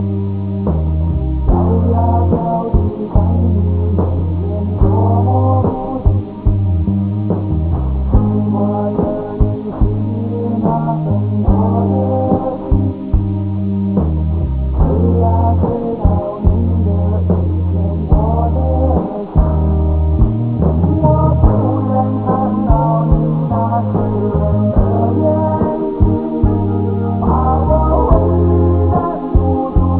30 seconds of singing
Cutoff frequency = 1000 Hz